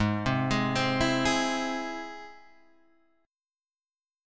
G# Augmented